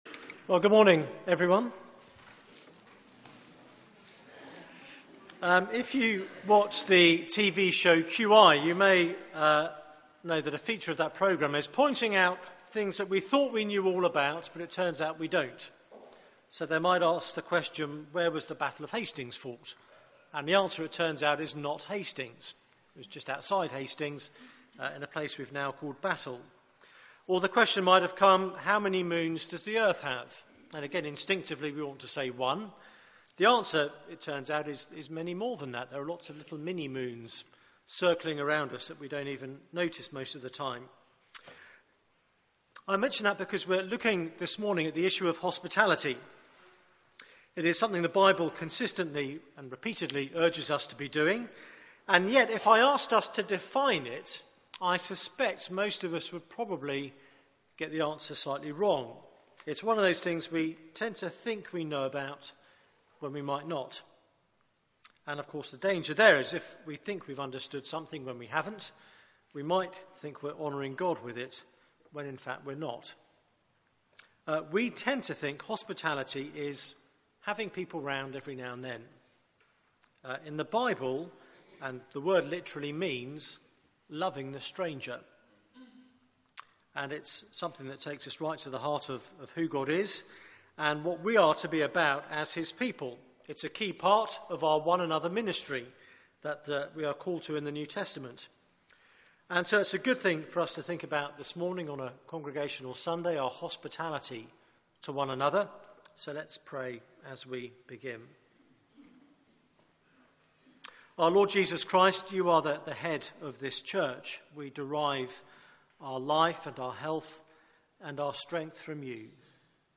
Media for 9:15am Service on Sun 11th Jan 2015
Theme: Practicing hospitality Sermon